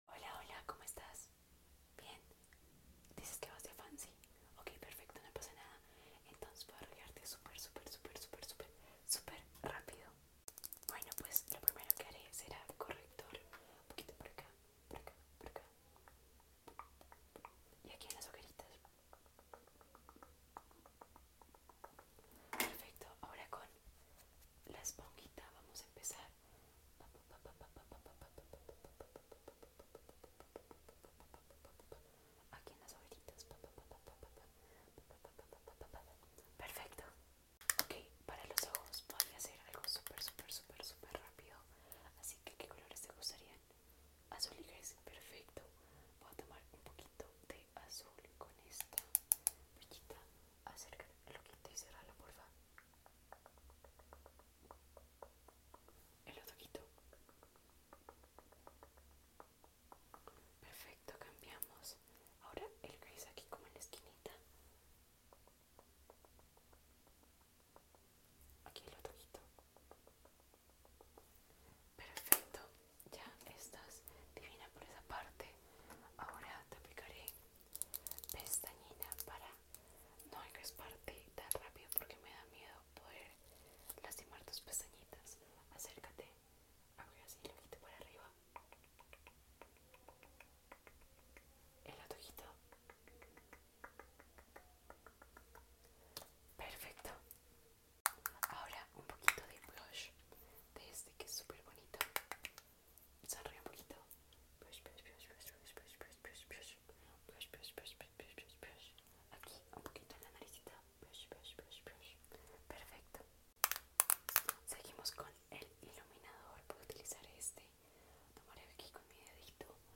ASMR Te arreglo rapido🤍 sound effects free download